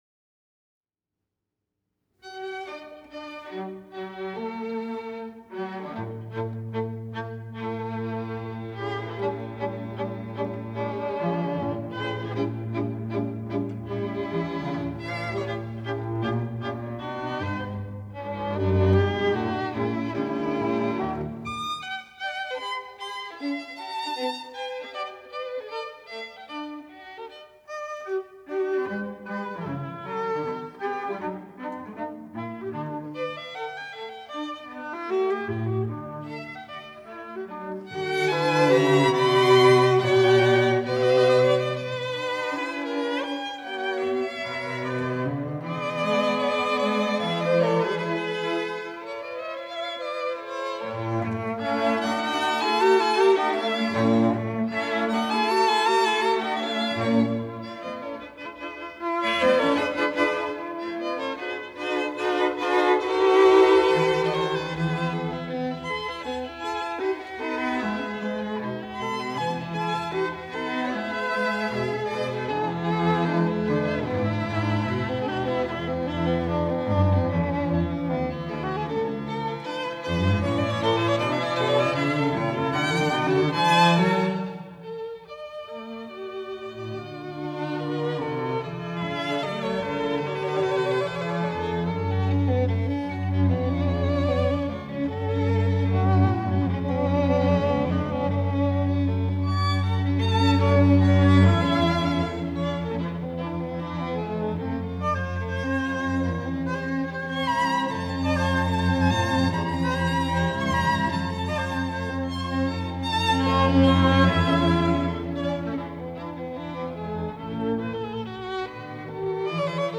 Viola
Violin
Cello
Recorded 15 March 1961 in the Sofiensaal, Vienna